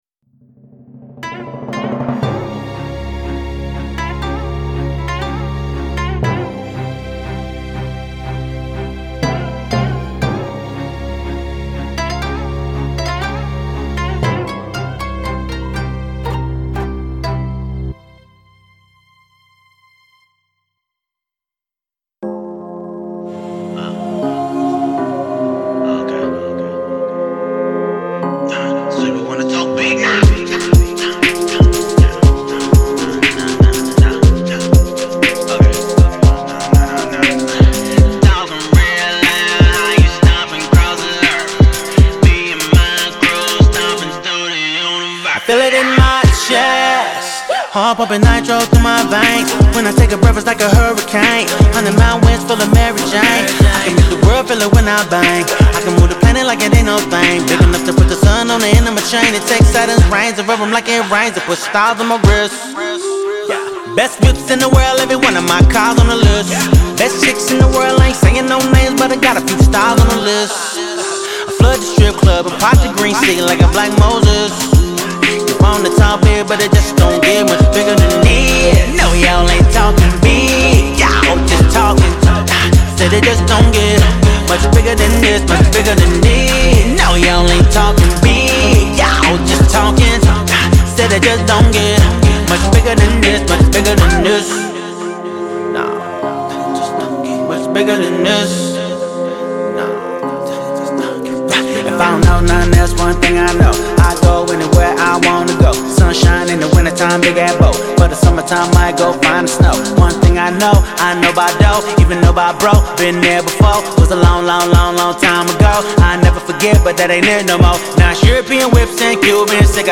это энергичная поп- и R&B-композиция